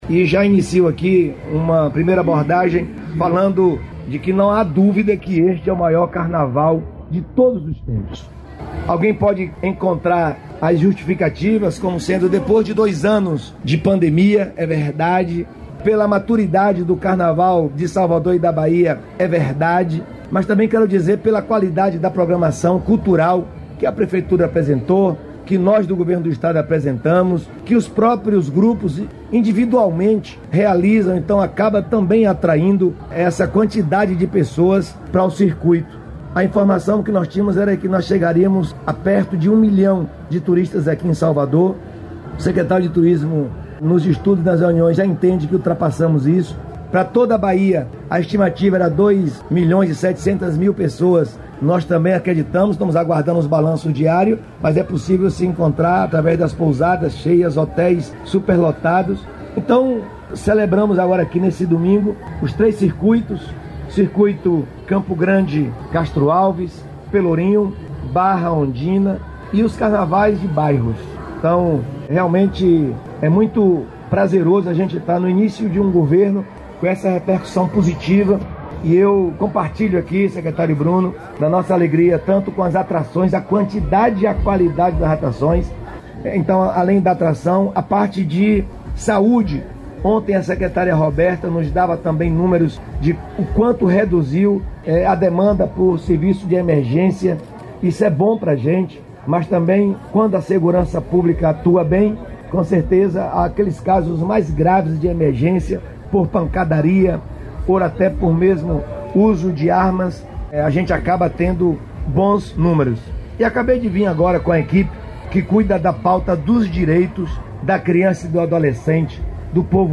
Durante coletiva de imprensa neste domingo (19) de Carnaval em Salvador, o governador Jerônimo Rodrigues destacou a organização da folia em Salvador.